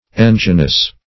Search Result for " enginous" : The Collaborative International Dictionary of English v.0.48: Enginous \En"gi*nous\, a. [OF. engignos.